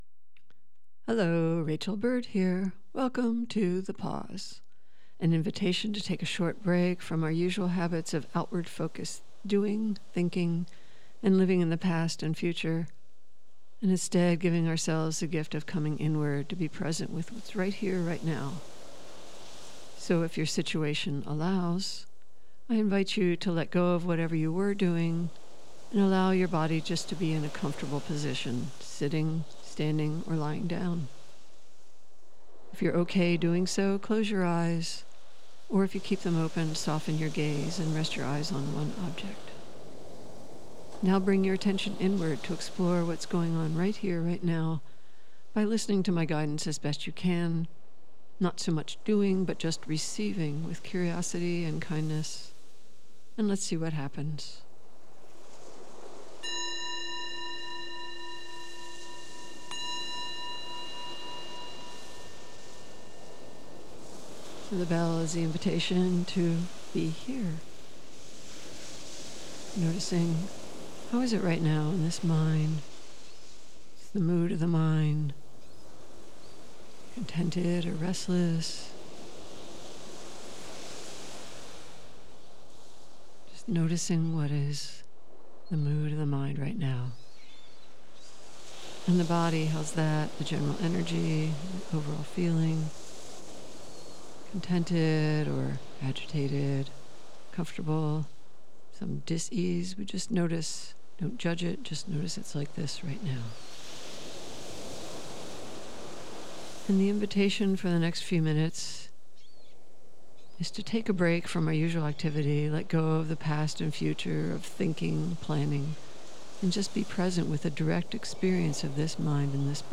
Meditation: Present moment experience, and what gets in the way of it.